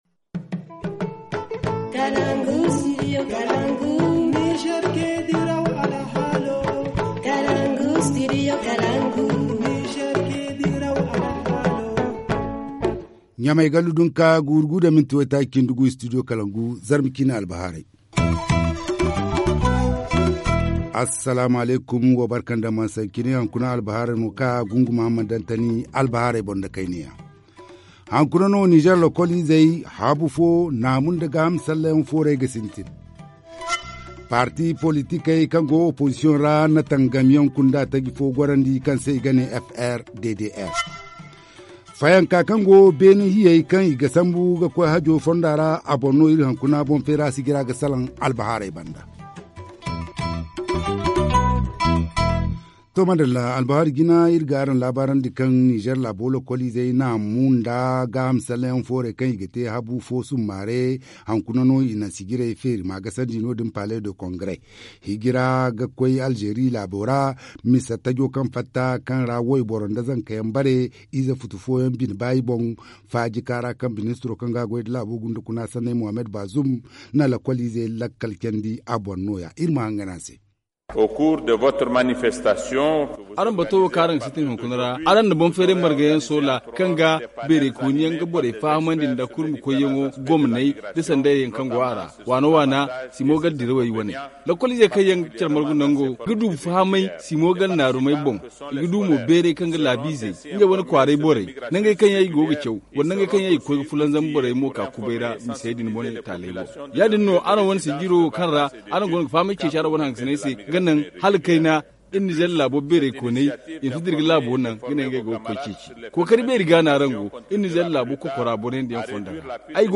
1. Ouverture ce matin, à Niamey, de la 5ème édition de la semaine Culturelle et Sportive des scolaires nigériens : L’apparition d’une nouvelle forme de migration vers l’Algérie, …..C’est l’un des thèmes abordés ce matin par le ministre de l’intérieur Mohamed Bazoum, vous l’entendrez dans ce journal.